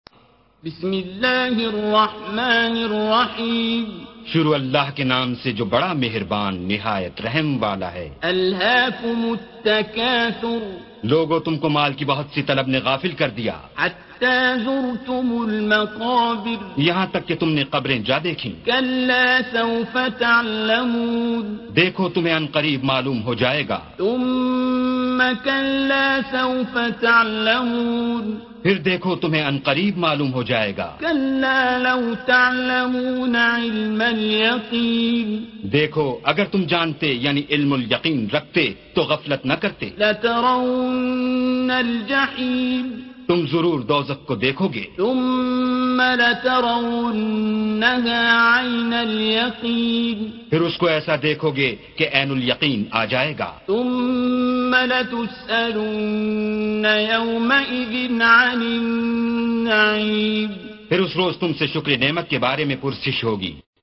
Surah Sequence تتابع السورة Download Surah حمّل السورة Reciting Mutarjamah Translation Audio for 102.